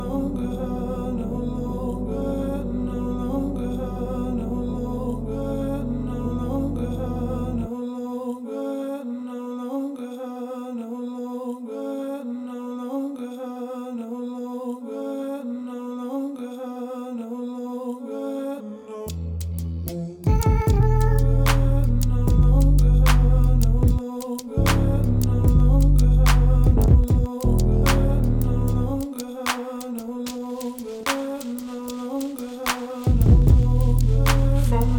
Жанр: Электроника